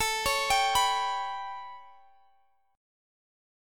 Listen to A9 strummed